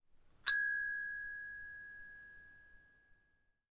Bell, Counter, A